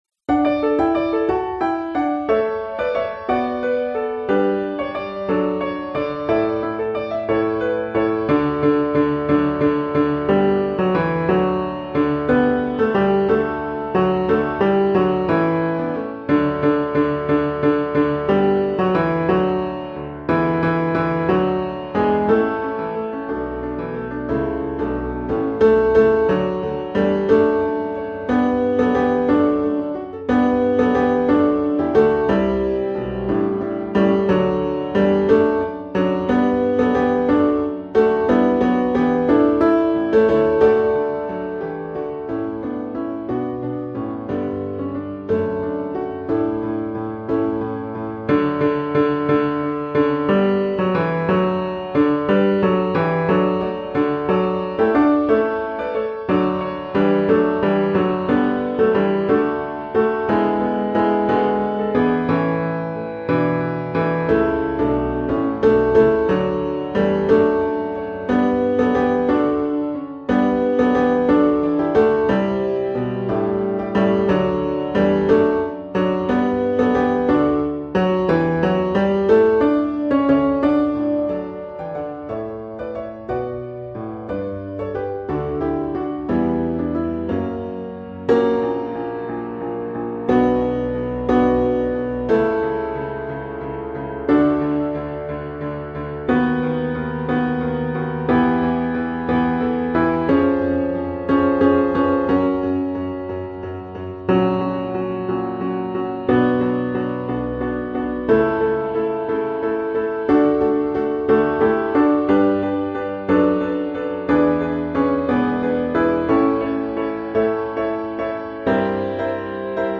GBC Choir Music - Joy Overflowing - Tenor
joy-overflowing-tenor